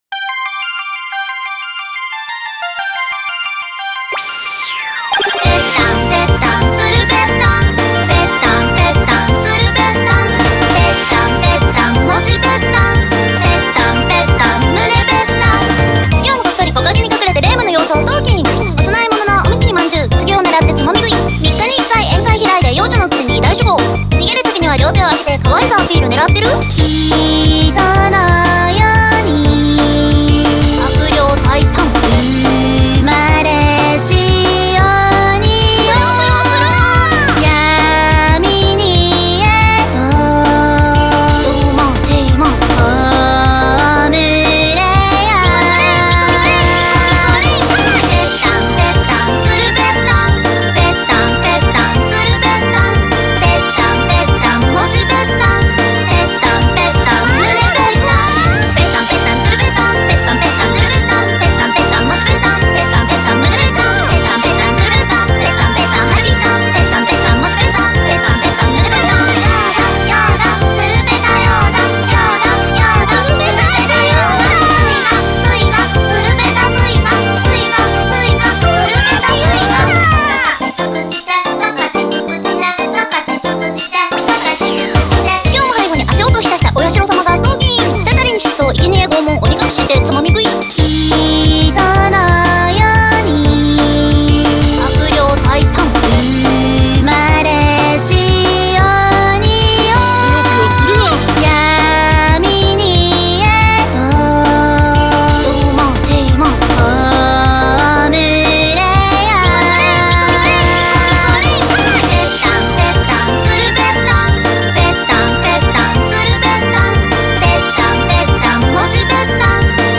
BPM180
Audio QualityCut From Video